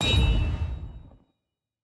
Minecraft Version Minecraft Version 25w18a Latest Release | Latest Snapshot 25w18a / assets / minecraft / sounds / item / trident / return3.ogg Compare With Compare With Latest Release | Latest Snapshot